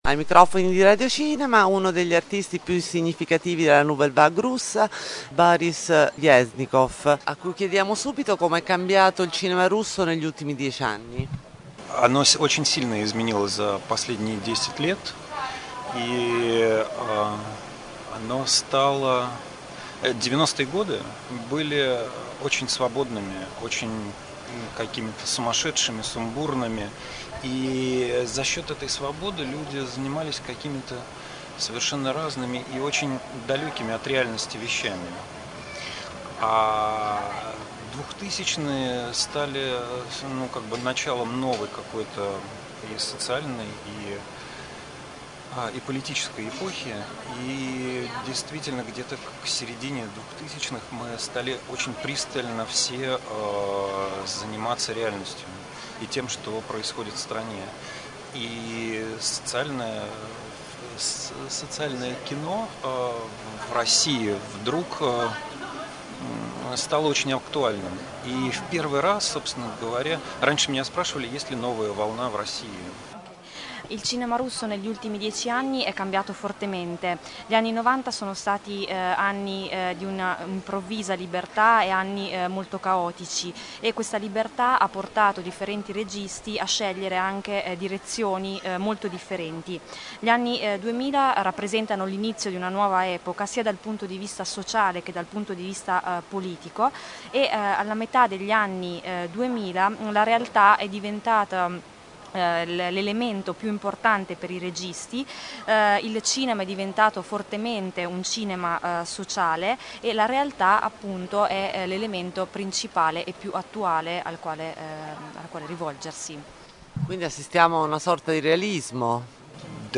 Le interviste ai protagonisti della sezione speciale sul cinema russo contemporaneo della 46° Mostra Internazionale del Nuovo Cinema – Pesaro Film Fest
Intervista_boris_klebnikov_Pesaro2010.mp3